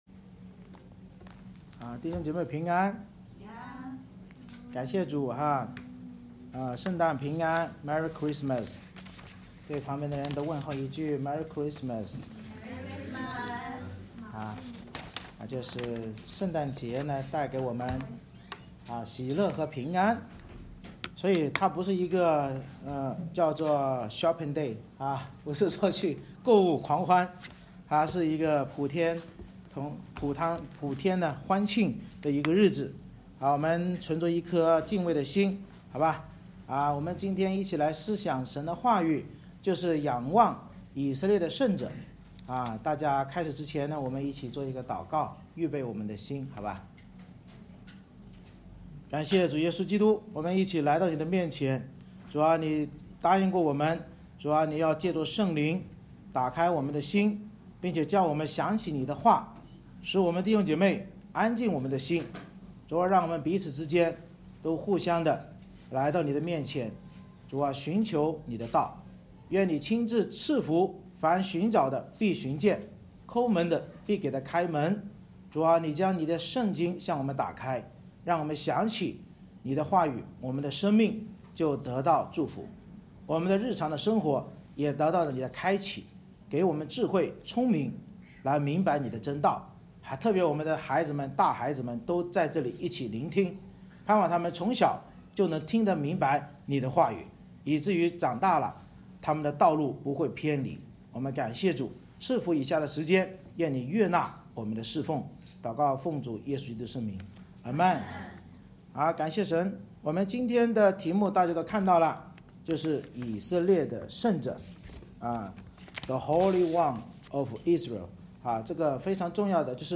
《以赛亚书》讲道系列 Passage: 以赛亚书Isaiah17:1-18:7 Service Type: 主日崇拜 先知领受大马士革的默示